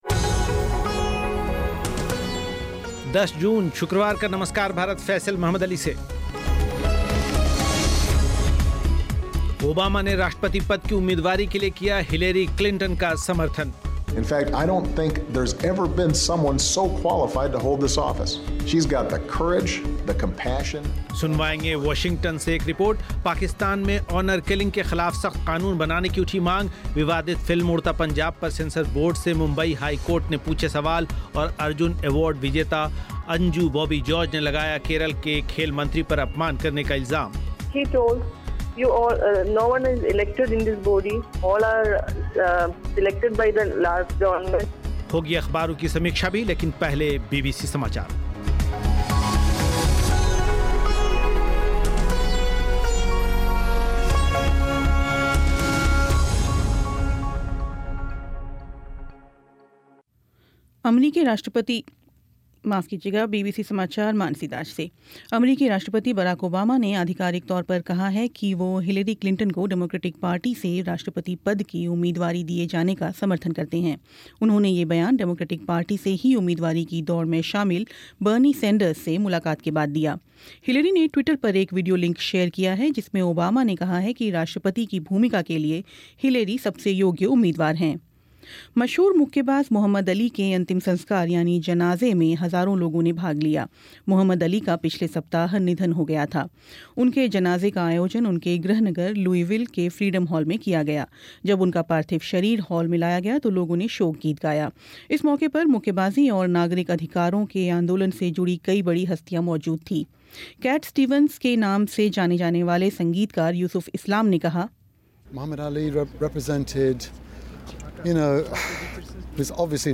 सुनें वाशिंगटन से एक रिपोर्ट पाकिस्तान में ऑनर किलिंग के ख़िलाफ़ सख़्त क़ानून बनाने की उठी मांग, लेकिन ऐसा वहां बार-बार होने की वजह क्या है? विवादित फ़िल्म उड़ता पंजाब पर सेसंर बोर्ड से मुंबई हाईकोर्ट ने पूछे सवाल और अर्जुन अवार्ड विजेता अंजु जार्ज ने लगाया केरल के खेल मंत्री पर अपमान करने का इल्ज़ाम, सुनें एक रिपोर्ट साथ ही अख़बारों की समीक्षा भी और बीबीसी समाचार